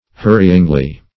hurryingly - definition of hurryingly - synonyms, pronunciation, spelling from Free Dictionary Search Result for " hurryingly" : The Collaborative International Dictionary of English v.0.48: Hurryingly \Hur"ry*ing*ly\, adv.